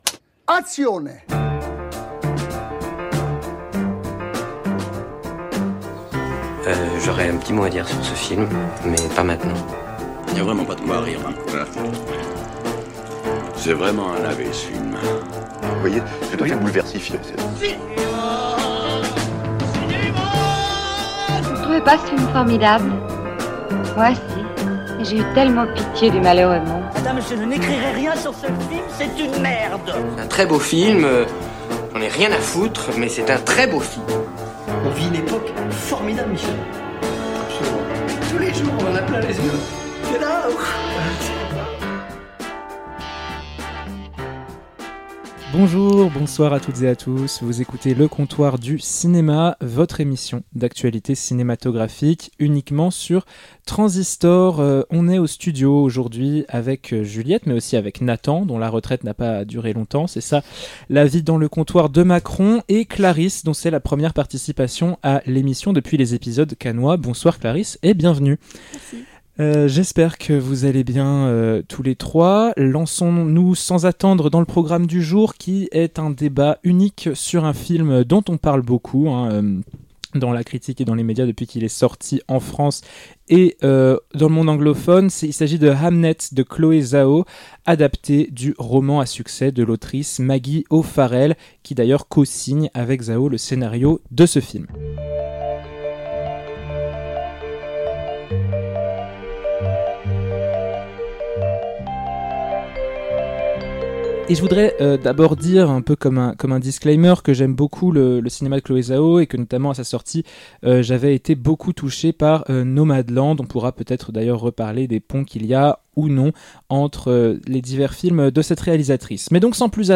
Vos critiques débattent face à un film digne d’indulgence pour certain·es, et lamentablement raté pour d’autres.